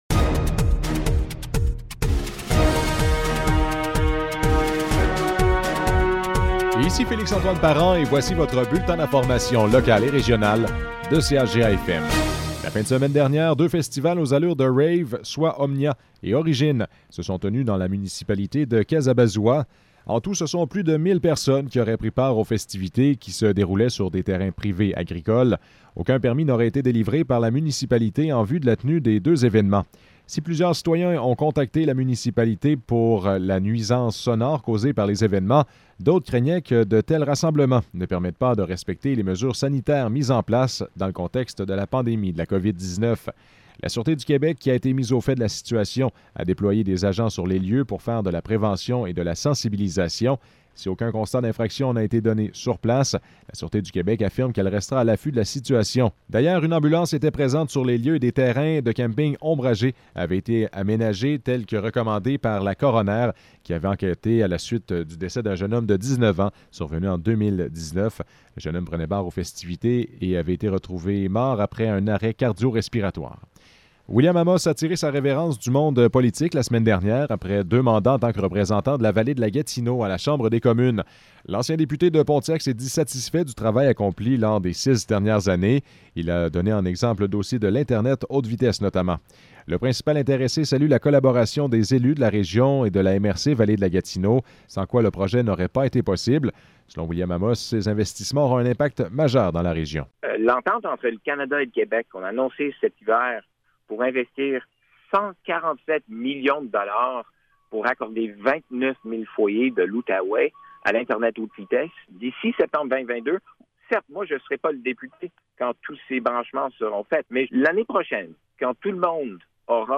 Nouvelles locales - 17 août 2021 - 12 h